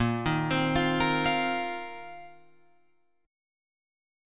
Bbsus2sus4 chord